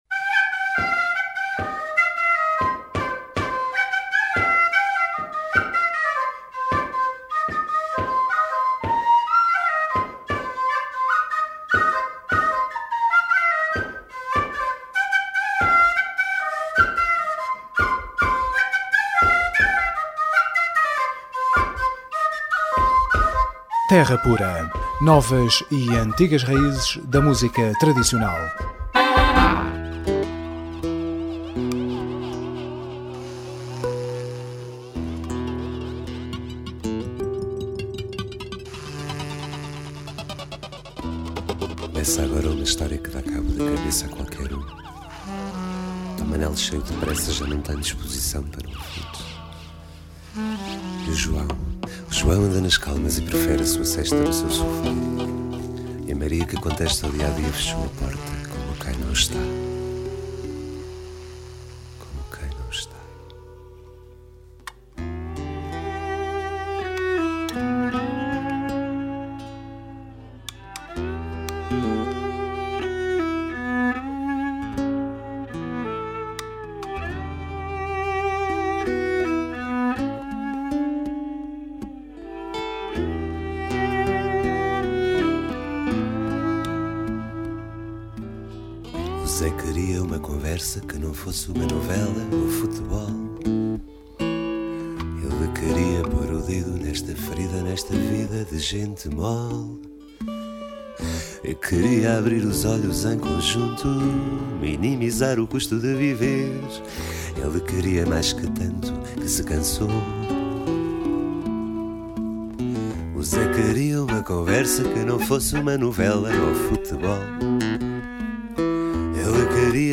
Terra Pura 20JUN11: Entrevista